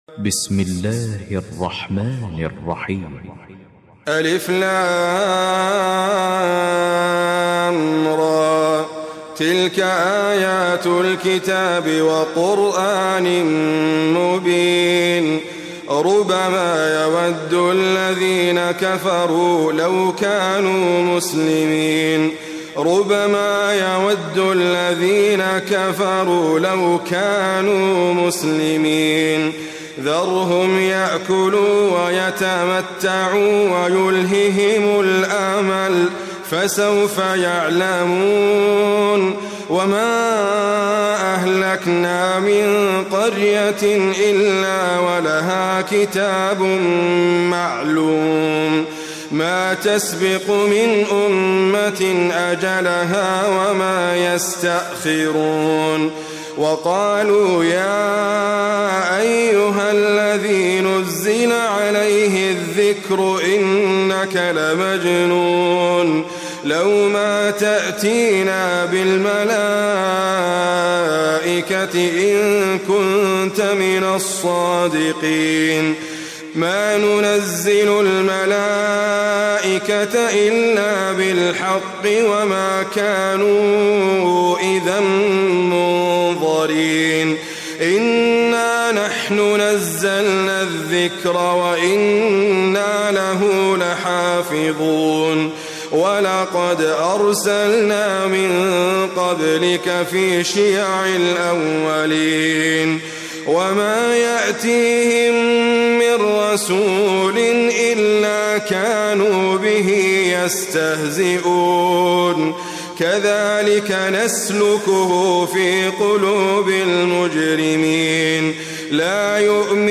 QuranicAudio is your source for high quality recitations of the Quran.